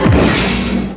KADUNK!!
pianoval.wav